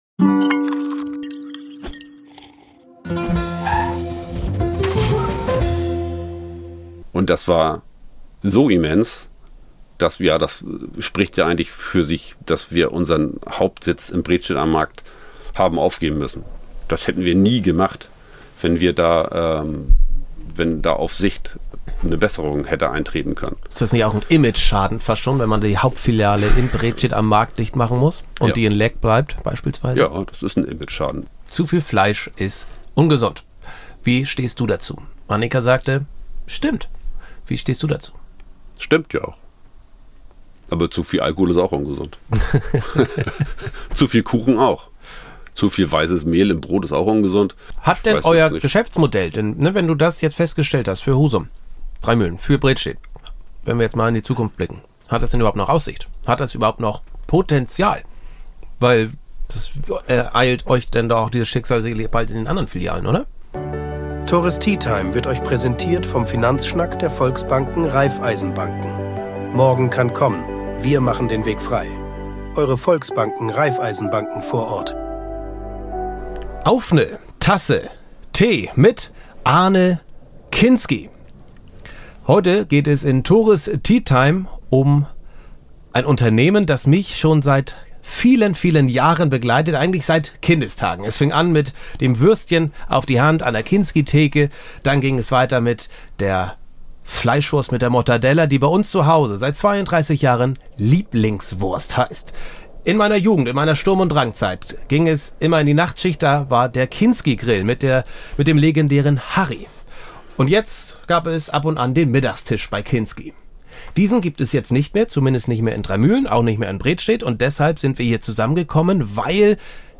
Es geht um die Preisentwicklung bei Würstchen, Löhne, den Wochenmarkt – und die Frage: Wie kann ein Traditionsbetrieb in Zeiten steigender Kosten und veränderten Konsumverhaltens bestehen? Das Gespräch fand im Podcast-Wohnmobil in Bredstedt